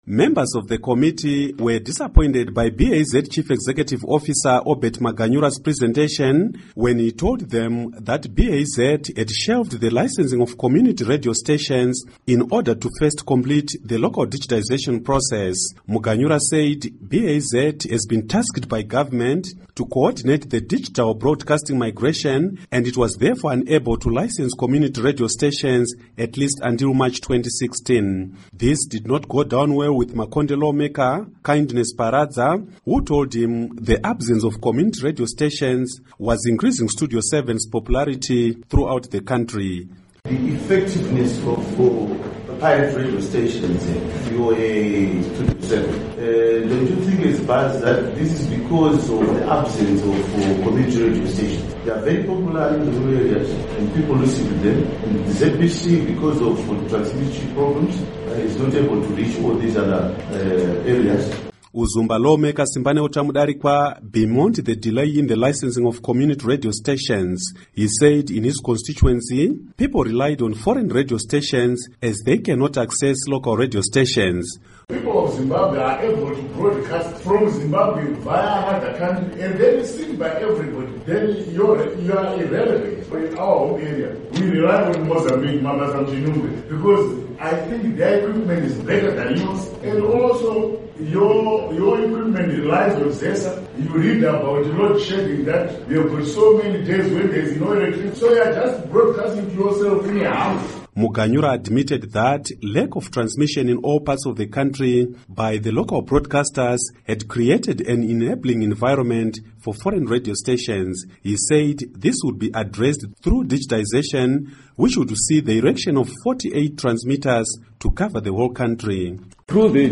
Report on Radio Licenses